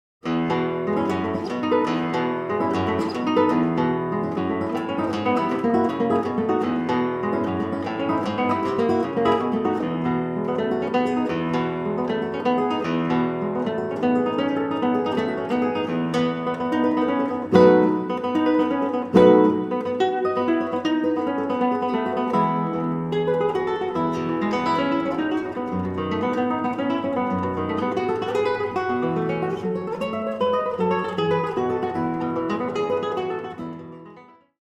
Solo Guitar and Guitar with String Orchestra